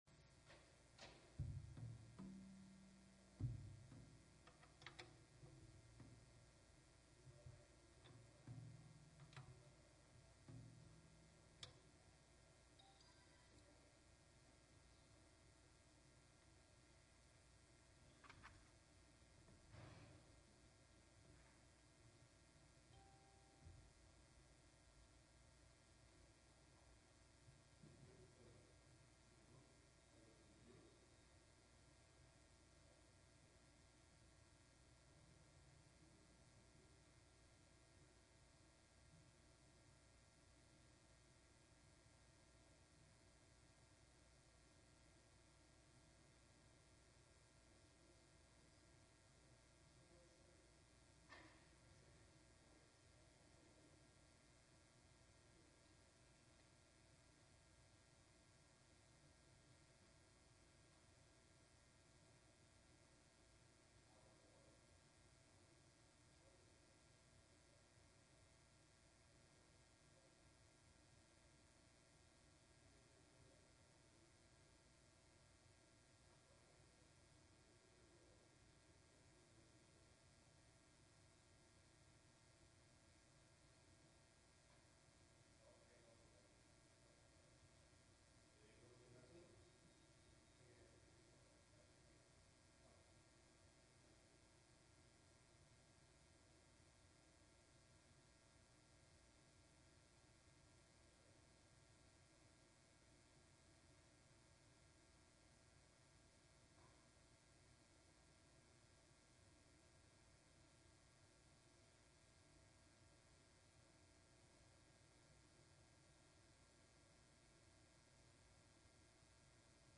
Genre Sermon or written equivalent